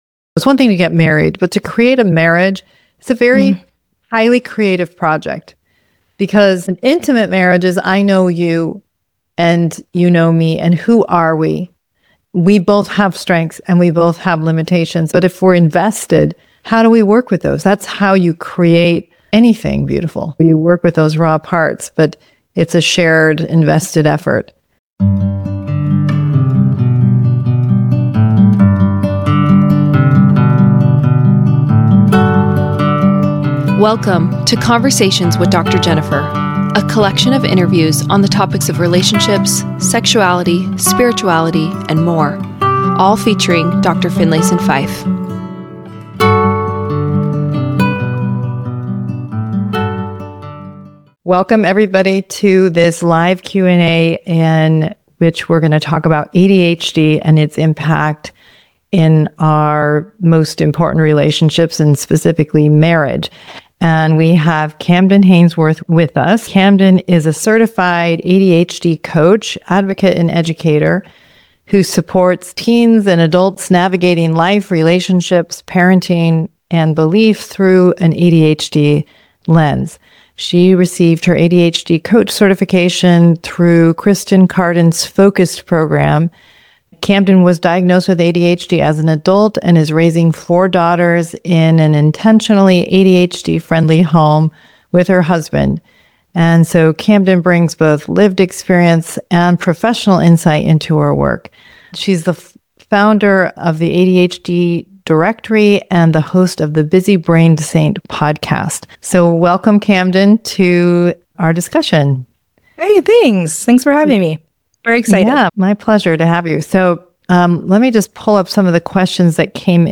Wired Differently | ADHD in Relationships Q&A